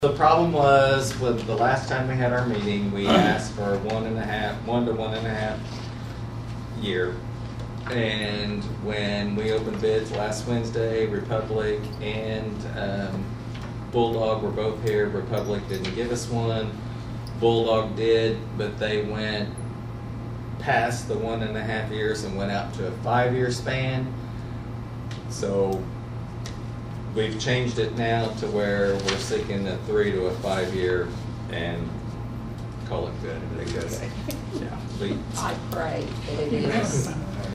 Finally, in what everyone hopes is the last time, the council once again approved the Mayor Jeff Pollard to seek bids for residential trash pickup within the municipality.  Mayor Pollard explains the latest issue in the ongoing saga.